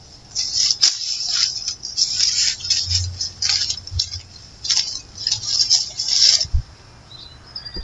树叶沙沙作响
描述：记录叶子沙沙作响
Tag: 现场录音 树木 OWI